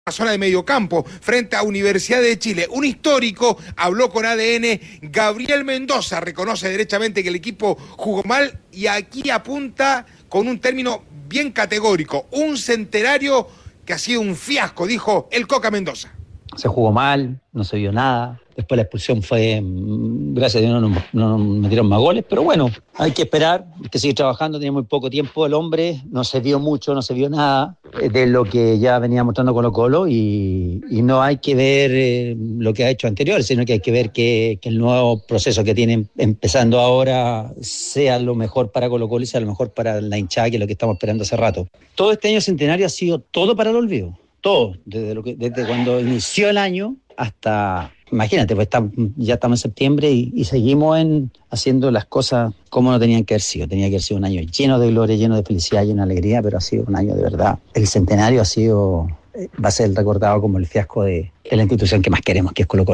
Gabriel “Coca” Mendoza, en conversación con ADN Deportes, lamentó la goleada sufrida por Colo Colo en la Supercopa.